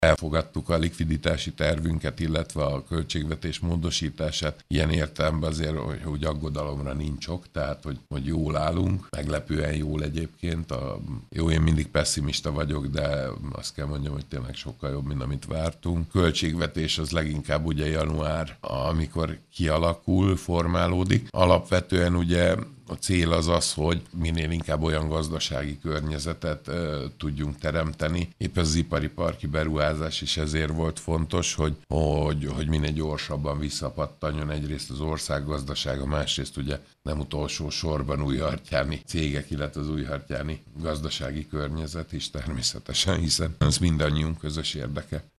Nincs ok aggodalomra Újhartyán gazdálkodásában. Schulcz József polgármester összegzésében elmondta, olyan gazdasági környezetet szeretne teremteni, hogy minél gyorsabban visszakerüljön a helyi gazdaság a korábbi évek szintjére.